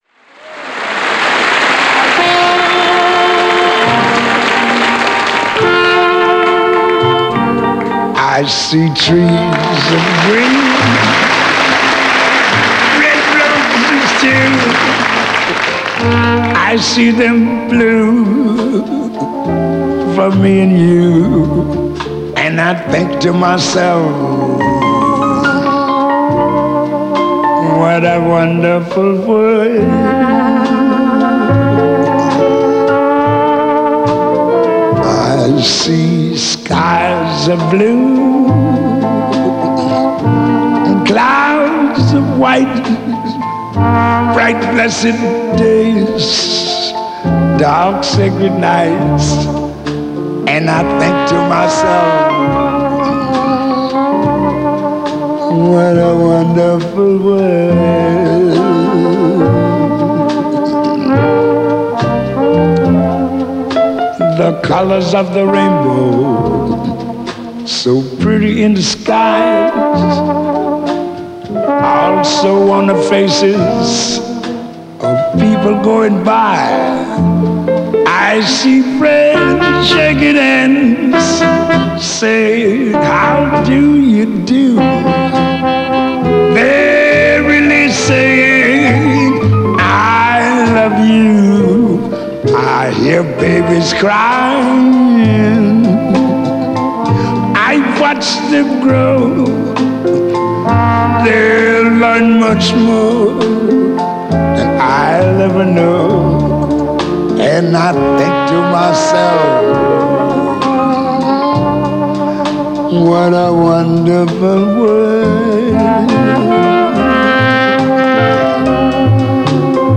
资格最老的爵士音乐大师。
很苍茫的声音。。。不错。。。。。。。。